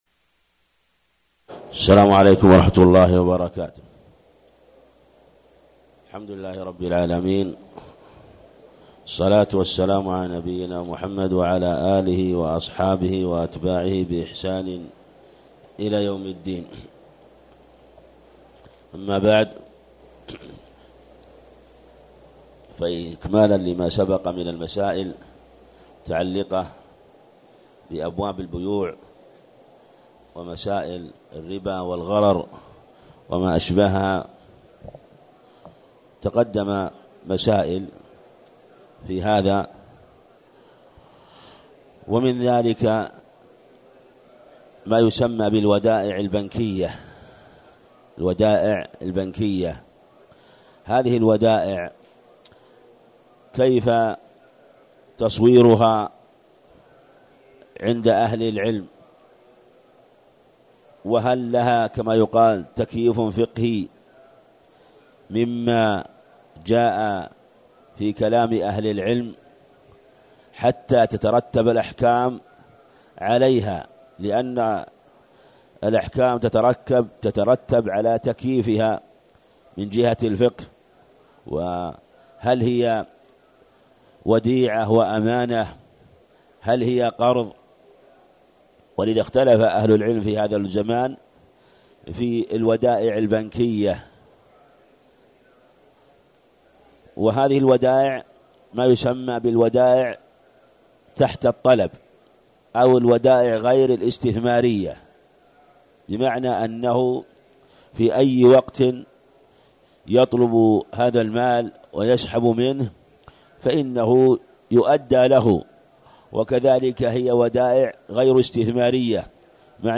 تاريخ النشر ١٦ رمضان ١٤٣٨ هـ المكان: المسجد الحرام الشيخ